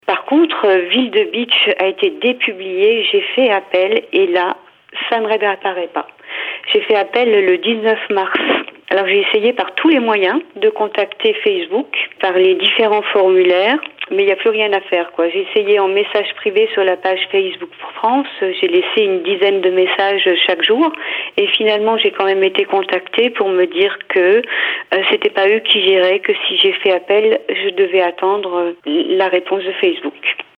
sur Radio Mélodie (son Radio Mélodie)